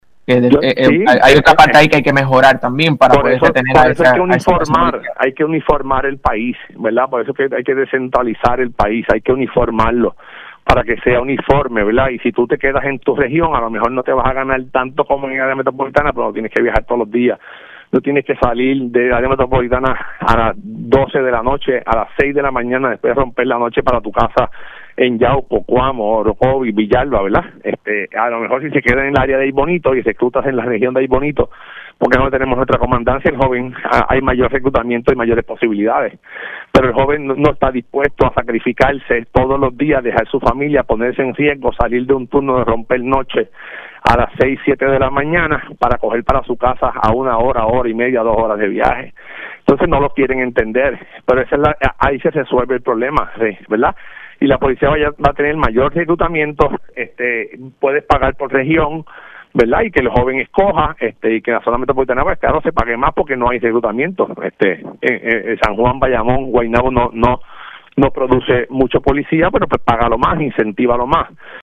308-JUAN-CARLOS-GARCIA-PADILLA-ALC-COAMO-INSISTE-EN-DESCENTRALIZACION-DEL-GOBIERNO.mp3